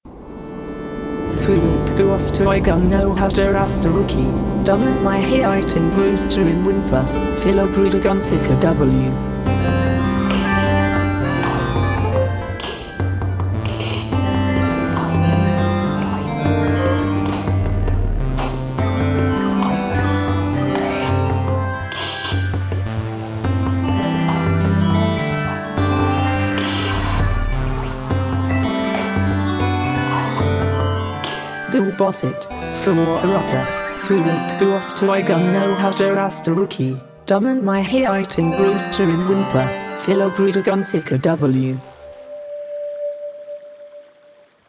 As usual, it's a mix of electronic and acoustic material.